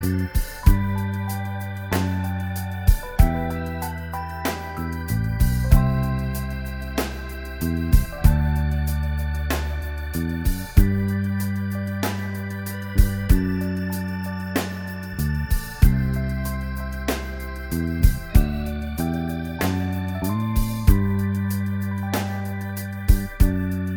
Minus Guitars Soft Rock 3:41 Buy £1.50